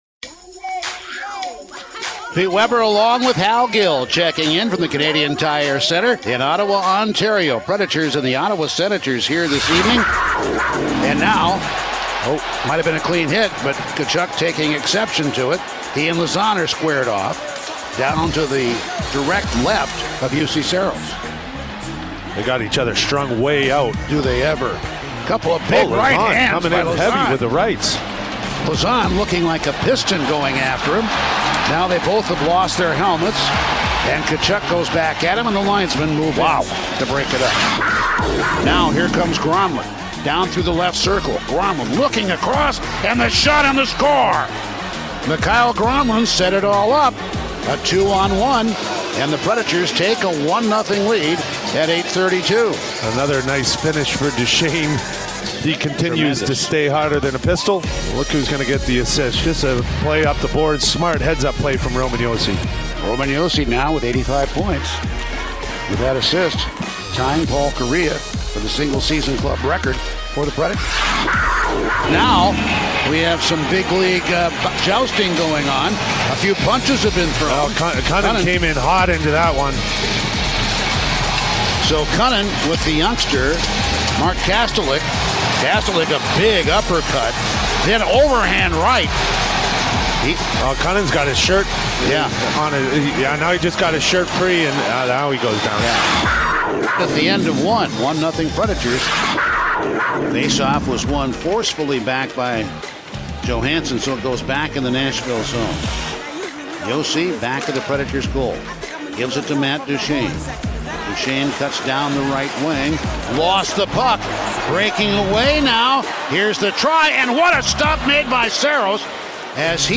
Radio highlights from the Preds' 3-2 win in Ottawa